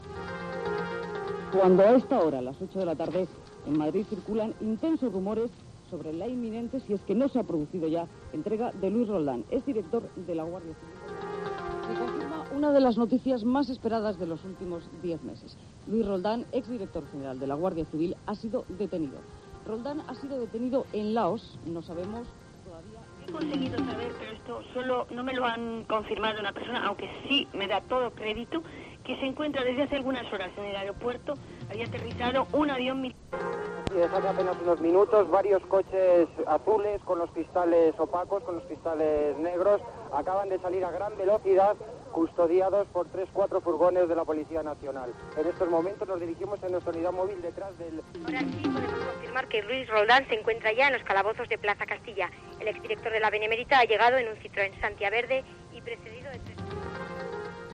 Los informativos de COPE se hicieron eco de la última hora tras la detención de Luis Roldán